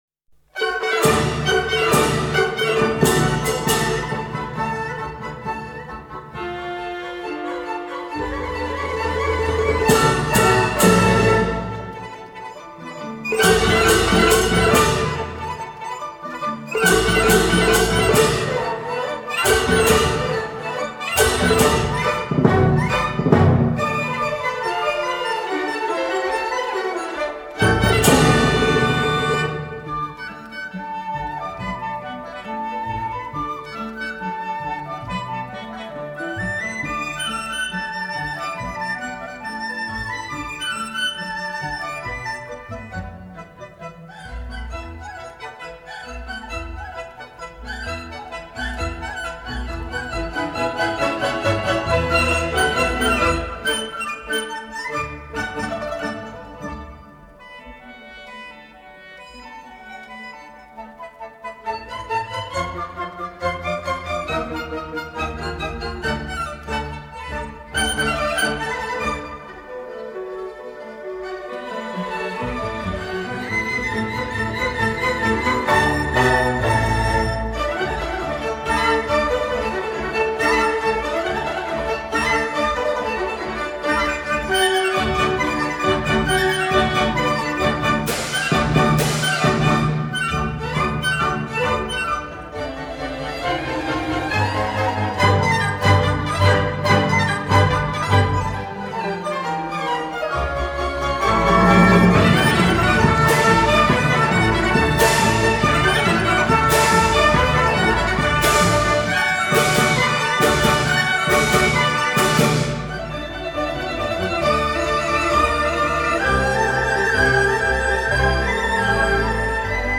民族音乐